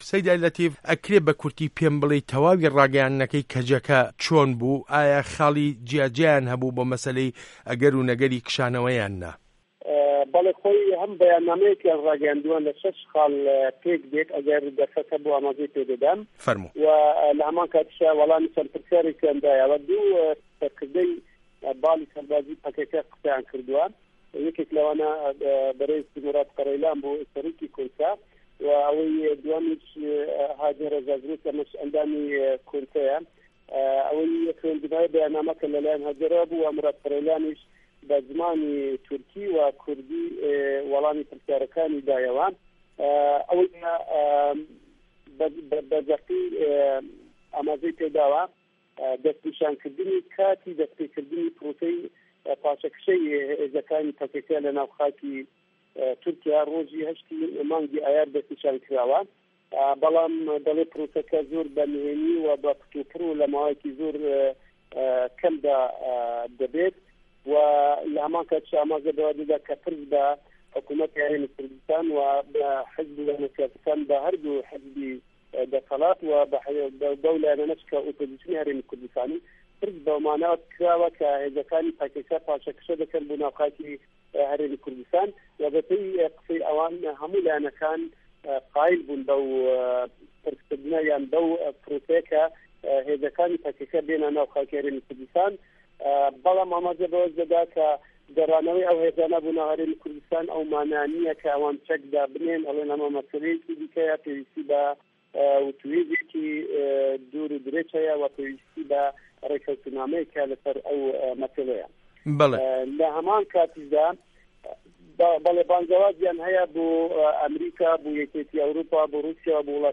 ڕاپـۆرتێـک له‌ باره‌ی ڕاگه‌یاندنی کشانه‌وه‌ی هێزه‌کانی په‌که‌که‌ له‌ تورکیا